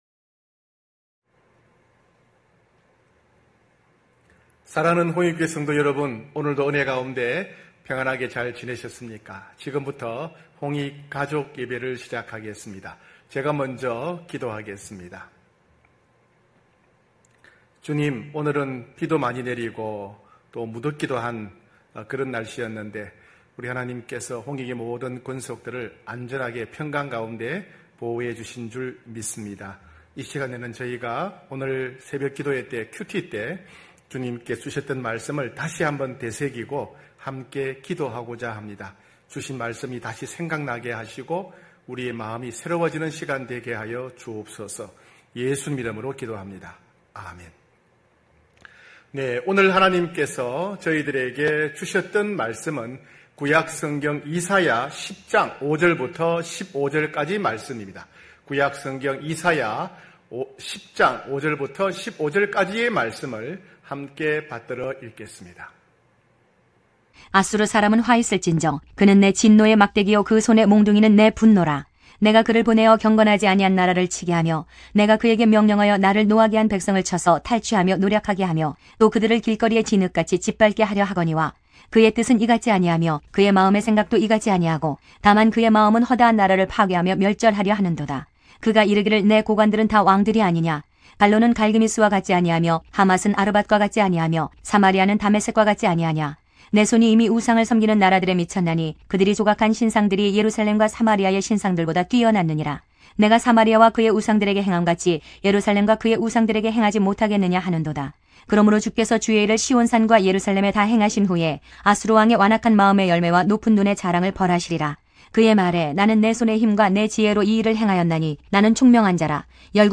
9시홍익가족예배(7월24일).mp3